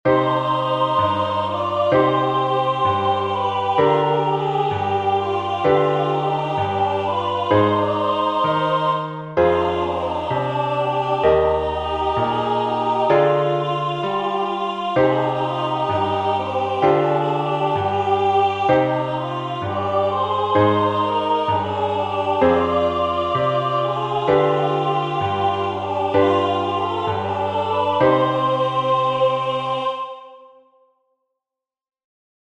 Entoación con acompañamento
Melodía e acompañamento:
entonacionpiano8,3.mp3